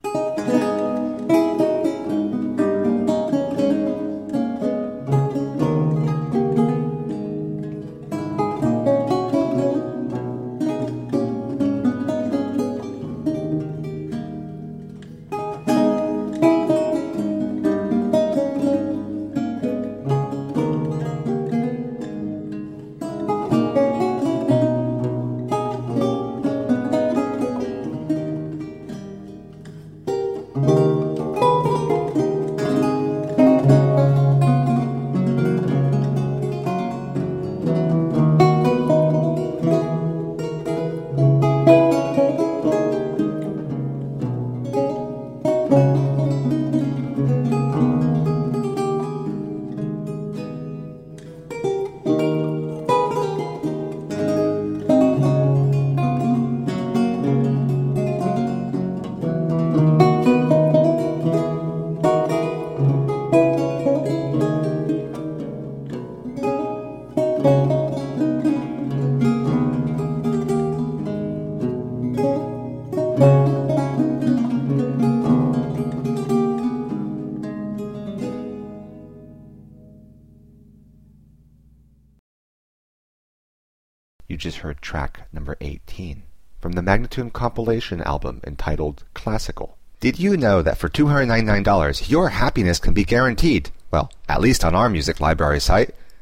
Duets for 2 Lutes in a minor and F Major Courante